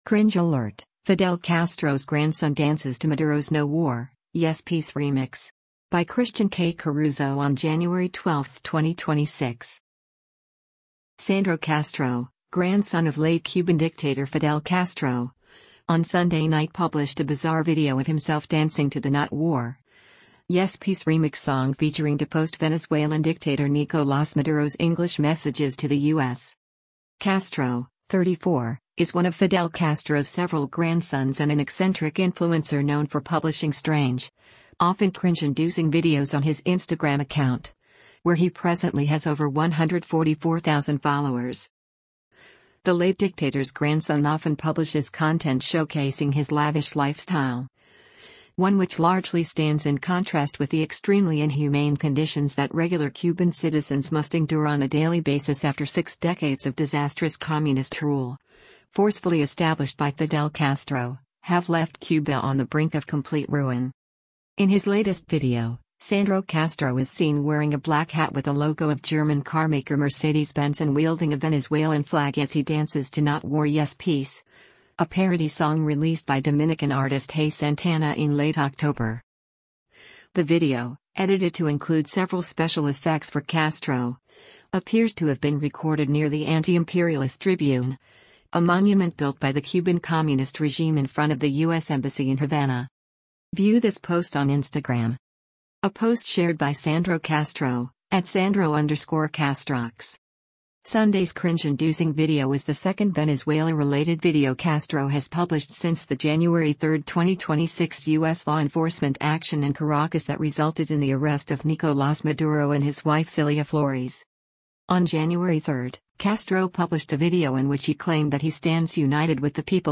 a parody song